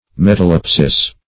Metalepsis \Met`a*lep"sis\, n.; pl. Metalepses.